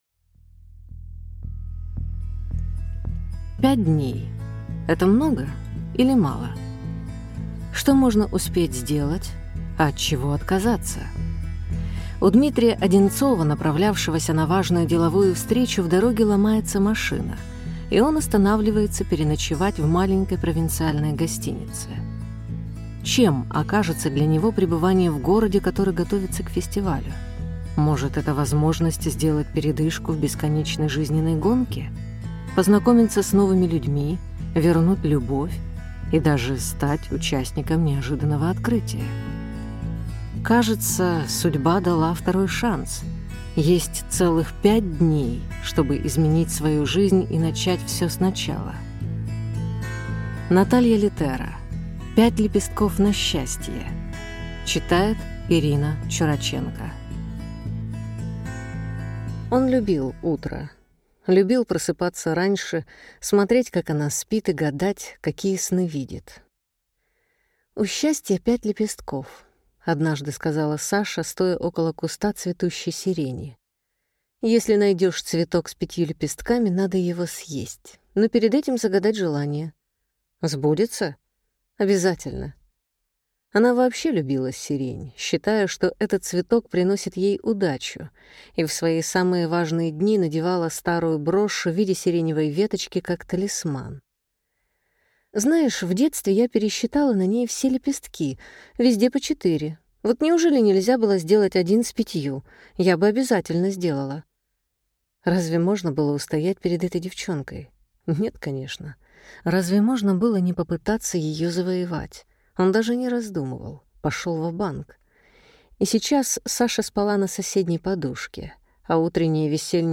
Аудиокнига Пять лепестков на счастье | Библиотека аудиокниг